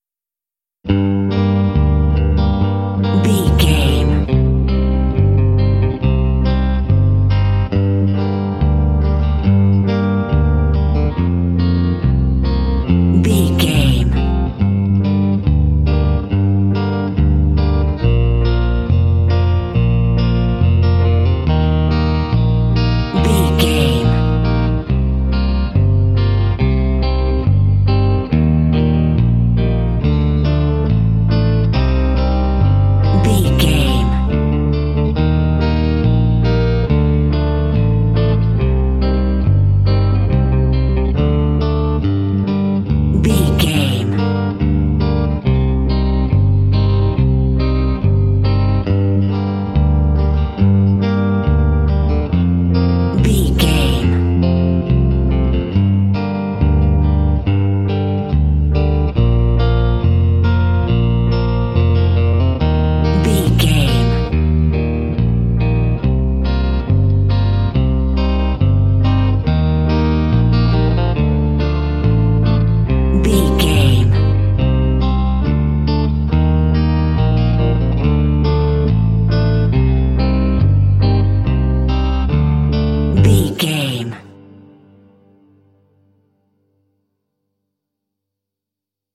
Aeolian/Minor
smooth
calm
groovy
electric guitar
bass guitar
piano
country